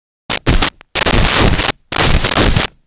Footsteps
Footsteps.wav